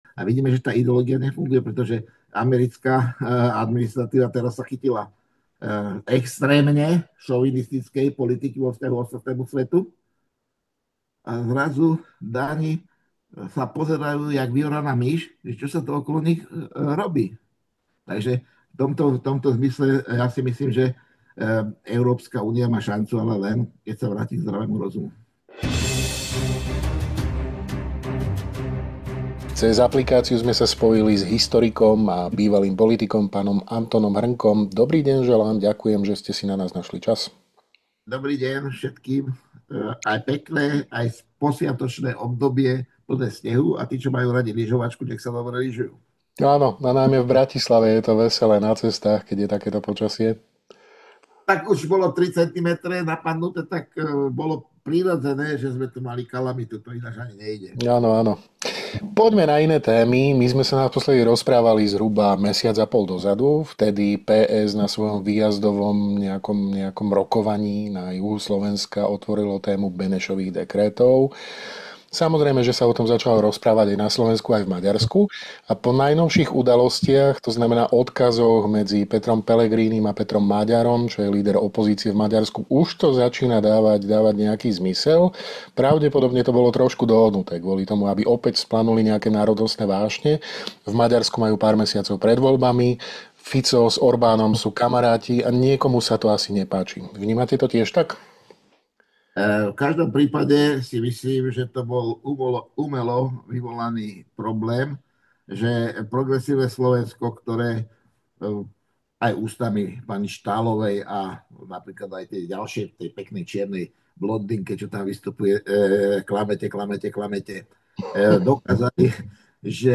Viac sa dozviete vo videorozhovore s PhDr. Antonom Hrnkom, CSc.